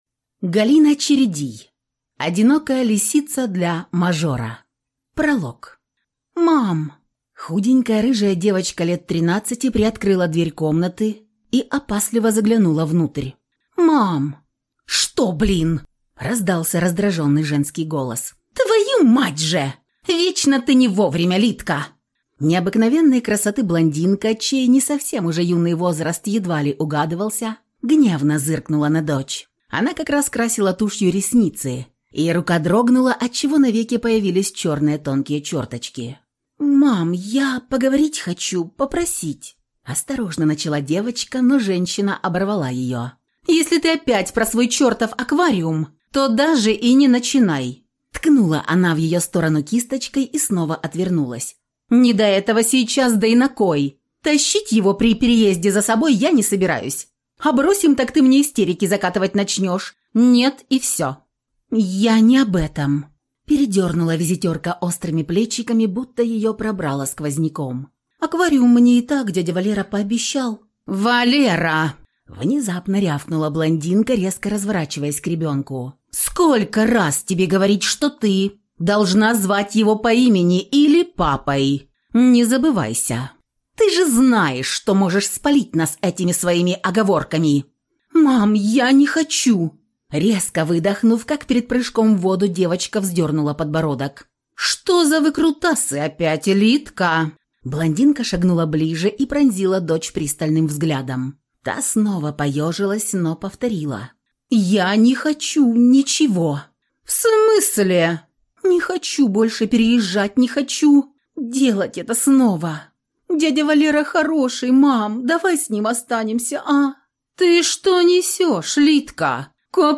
Аудиокнига Одинокая лисица для мажора | Библиотека аудиокниг